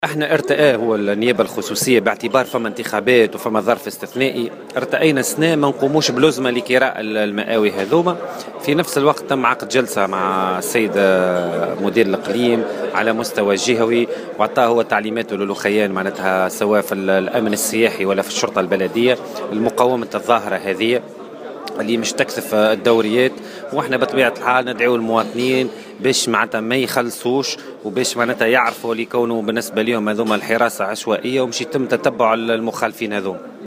وقال في تصريح لمراسلة "الجوهرة اف أم"